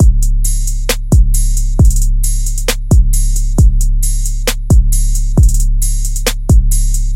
陷阱鼓循环
描述：Trap beat 808 drum loop.
Tag: 134 bpm Trap Loops Drum Loops 1.21 MB wav Key : C